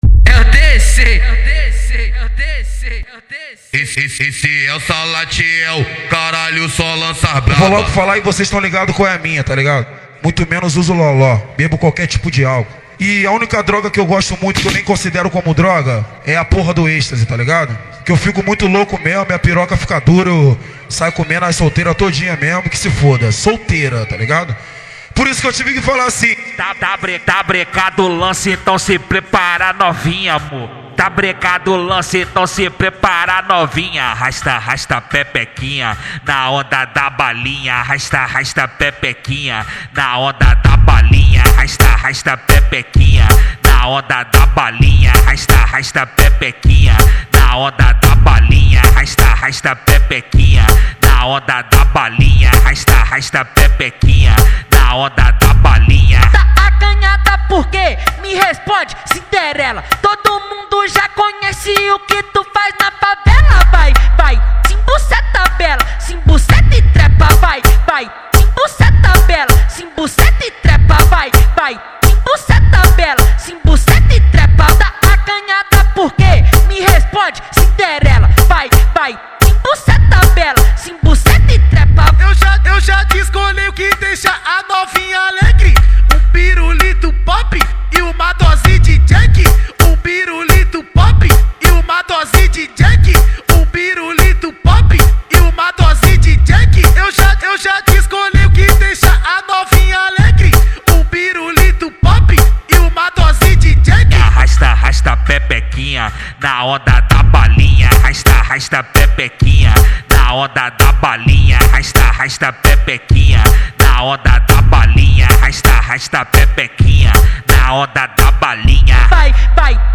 2025-01-26 14:07:20 Gênero: Funk Views